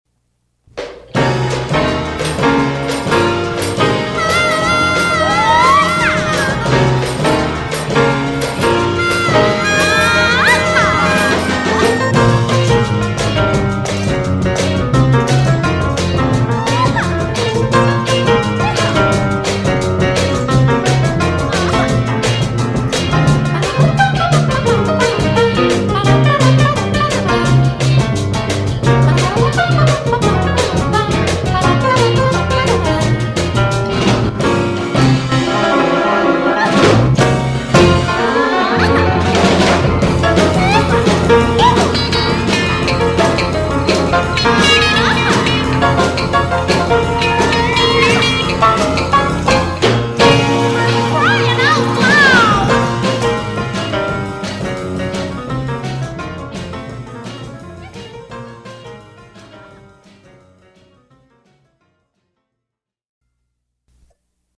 Piano Jazz Quintet